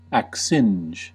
Ääntäminen
IPA : /ækˈsɪndʒ/